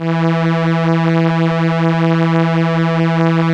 Index of /90_sSampleCDs/Keyboards of The 60's and 70's - CD1/STR_Elka Strings/STR_Elka Cellos
STR_ElkaVcE_4.wav